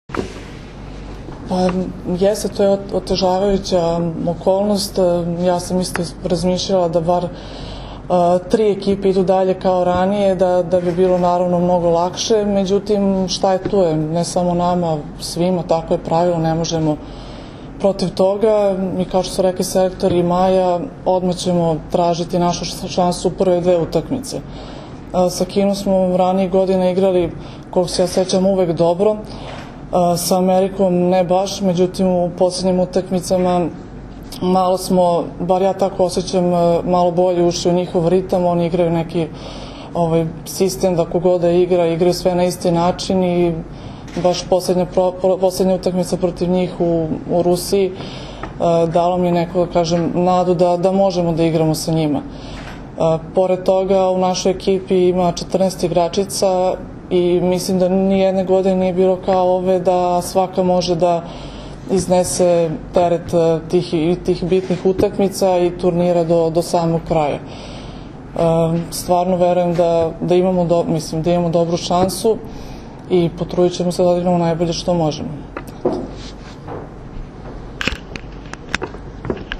Tim povodom danas je u beogradskom hotelu „M“ održana konferencija za novinare, kojoj su prisustvovali Zoran Terzić, Maja Ognjenović, Jelena Nikolić i Milena Rašić.
IZJAVA JELENE NIKOLIĆ